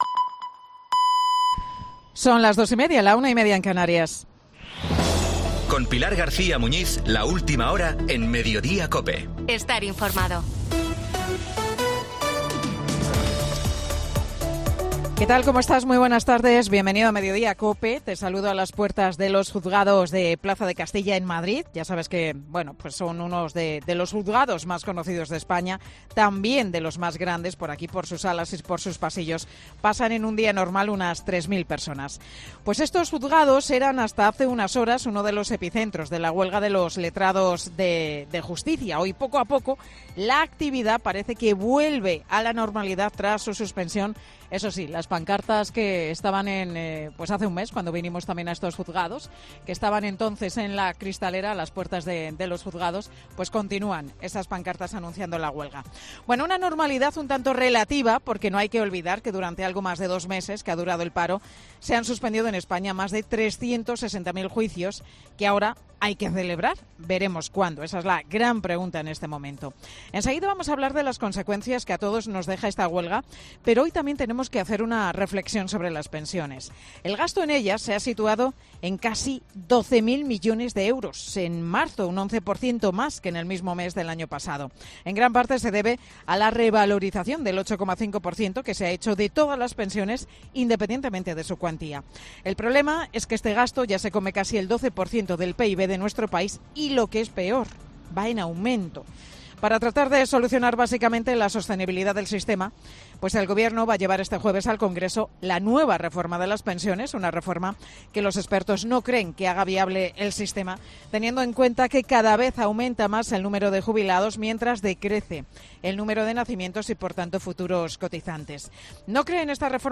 Monólogo de Pilar García Muñiz